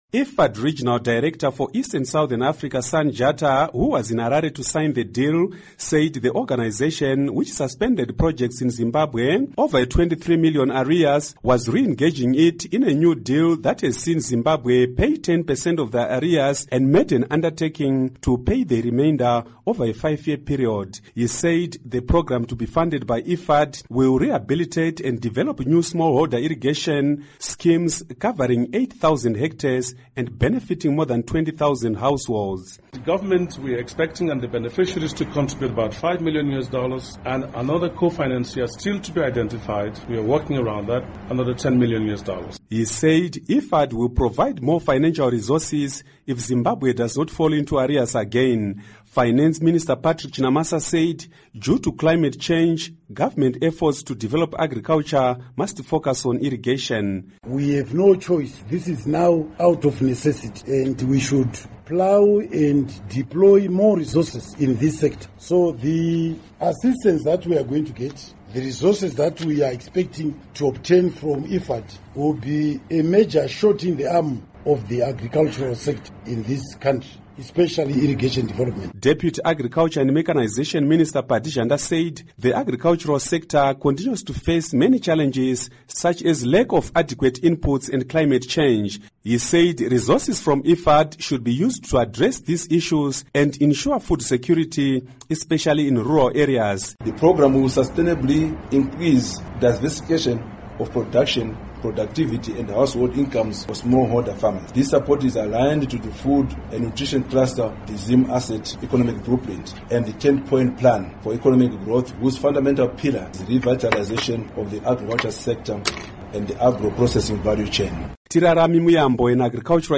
Report on Irrigation Funding